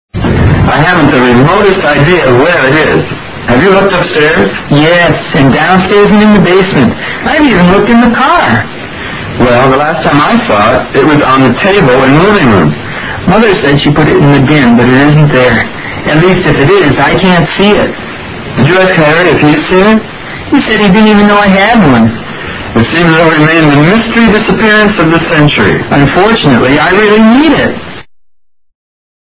英语对话听力mp3下载Listen 19:WHERE IS IT?